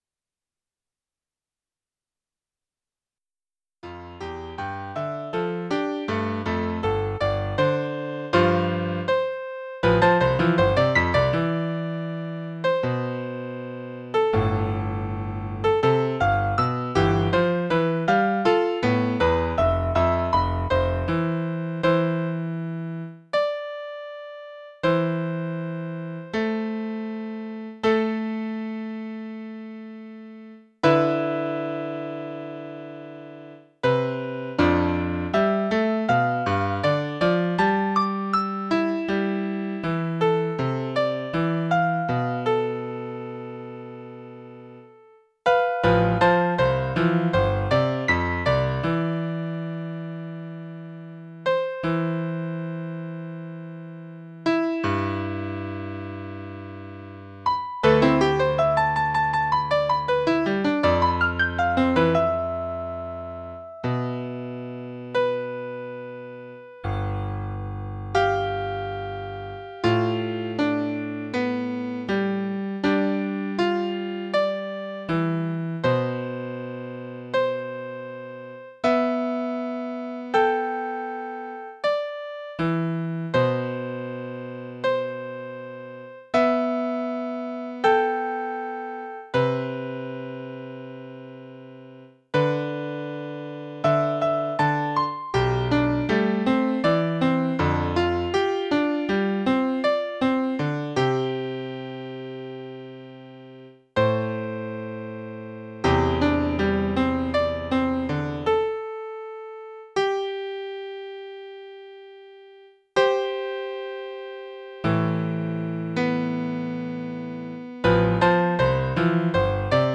Estilo : Instrumental